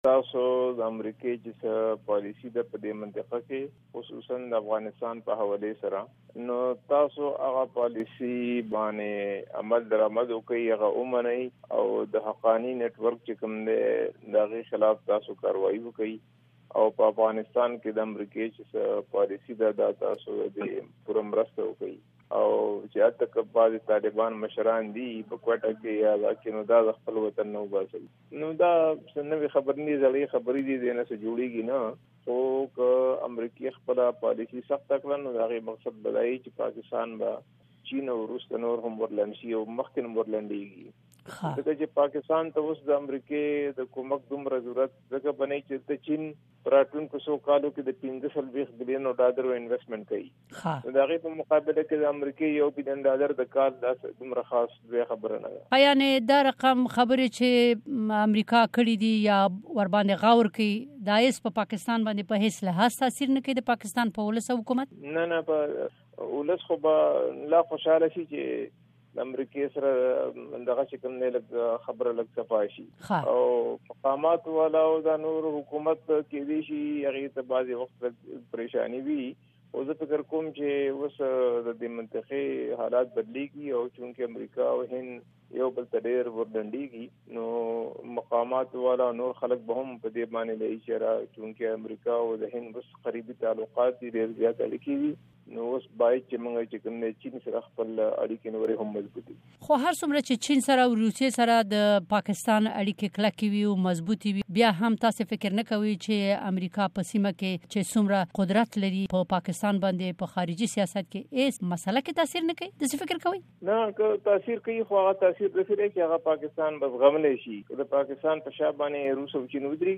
د رستم شاه مومند سره مرکه